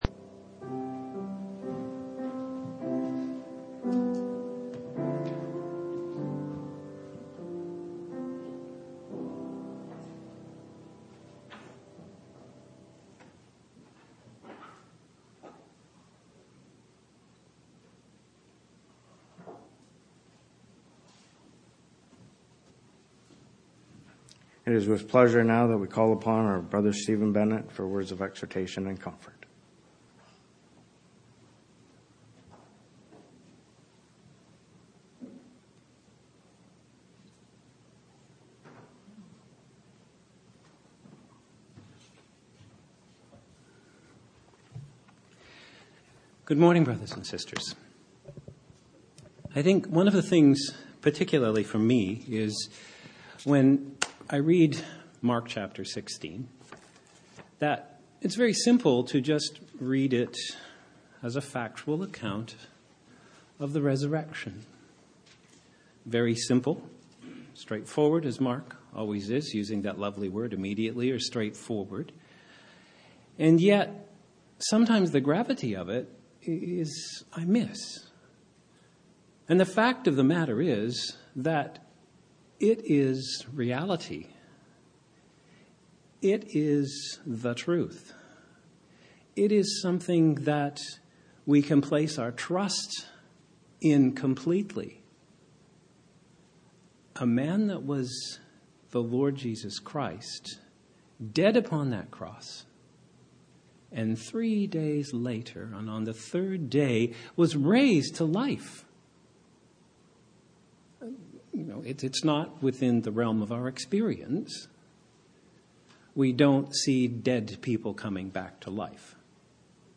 Exhortation 08-21-16